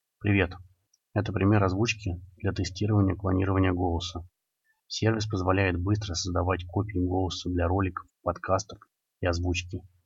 Мы собрали оригинальную запись и три клона, чтобы вы могли сами послушать и сравнить качество.
Оригинальный голос
ORIGINAL_VOICE.mp3